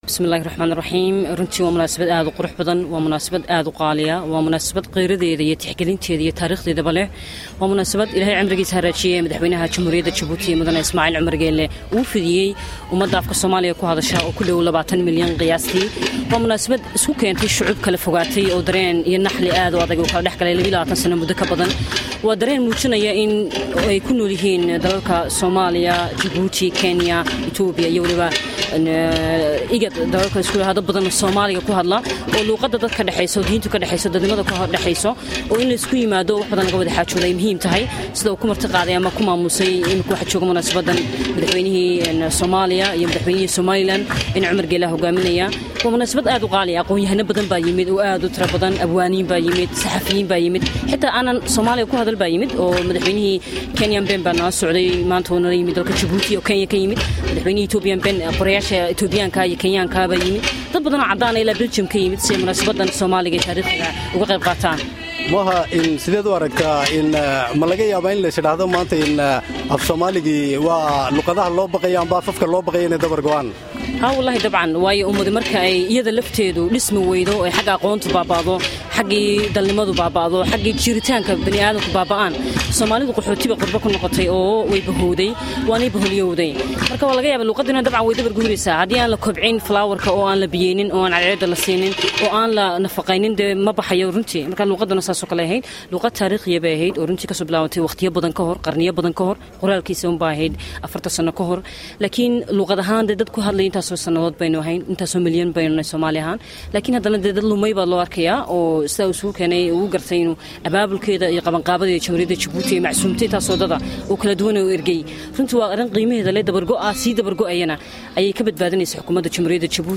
Dhageyso Wareysiga 40 guurada Qorista Far Soomaaliga